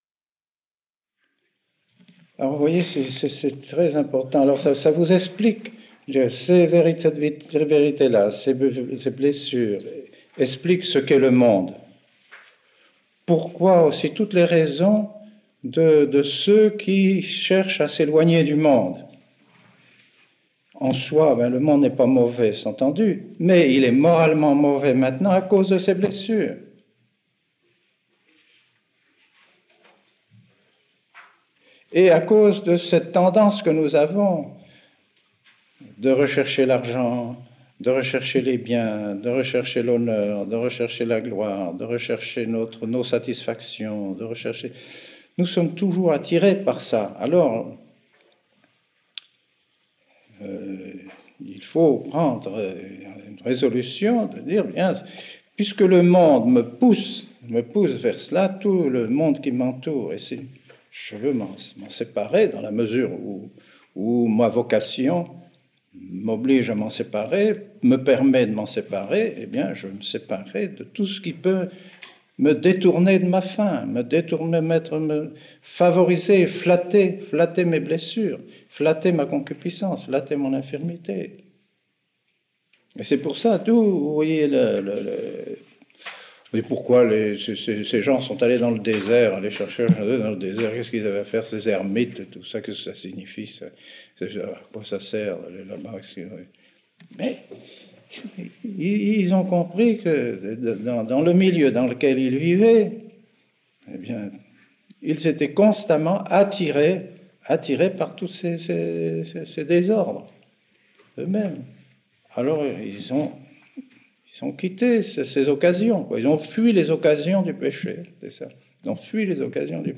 Sermon hors série ~ Le péché originel par Mgr Lefebvre